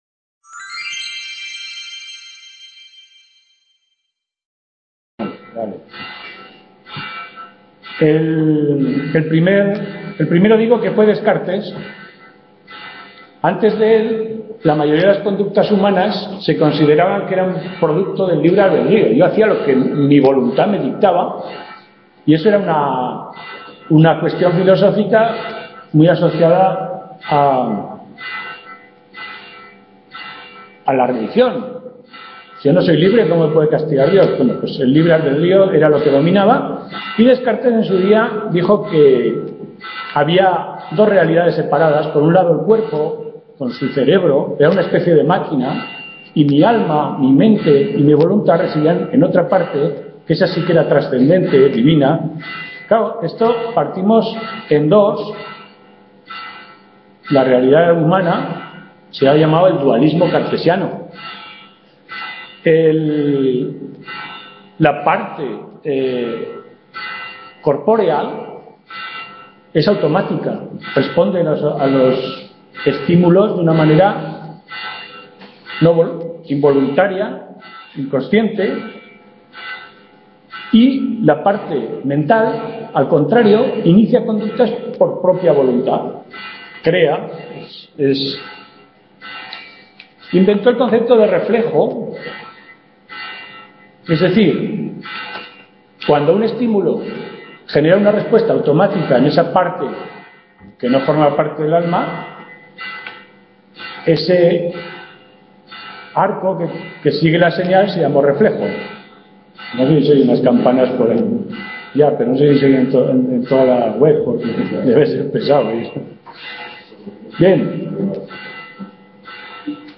Tutoría: Calatayud Aprendizaje 2013-02-25 segunda parte | Repositorio Digital